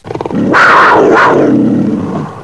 1cat.wav